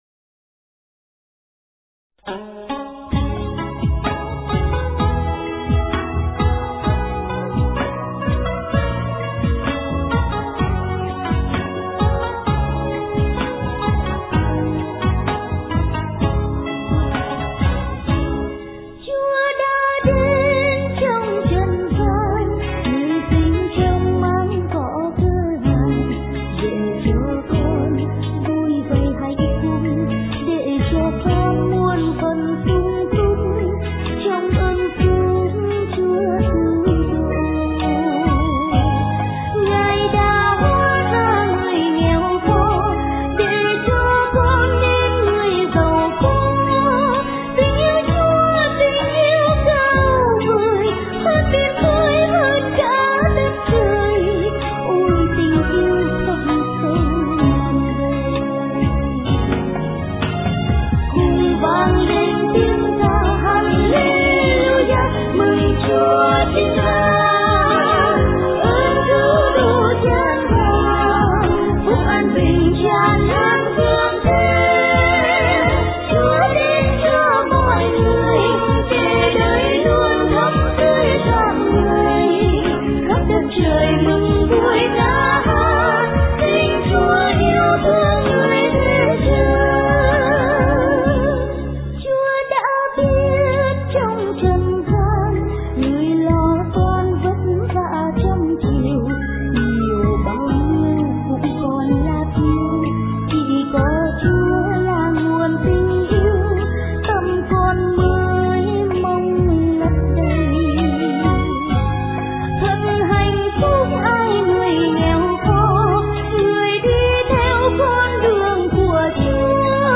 * Thể loại: Giáng Sinh